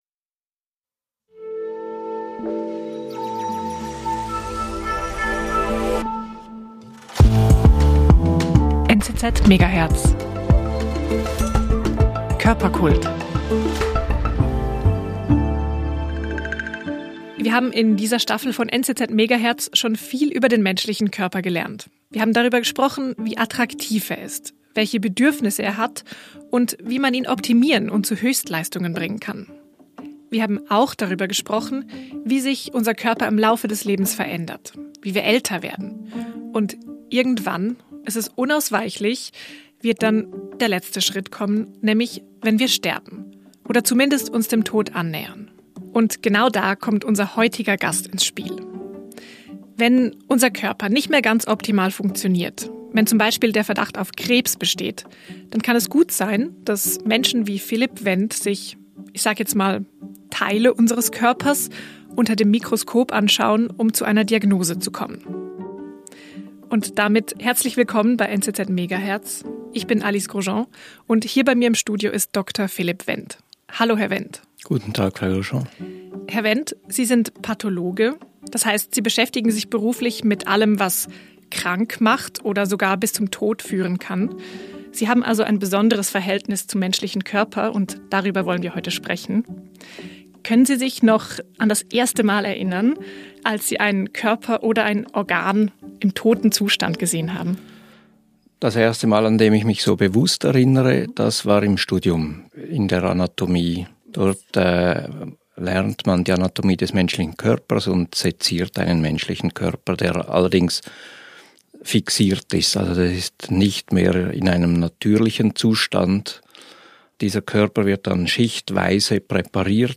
Unsere Hosts fragen sich das auch und sprechen mit Menschen, die Antworten gefunden haben. Jede Staffel nehmen wir uns ein grosses Thema vor und decken überraschende Fakten und Perspektiven auf.